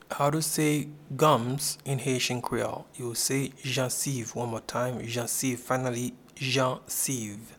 Pronunciation and Transcript:
Gums-in-Haitian-Creole-Jansiv.mp3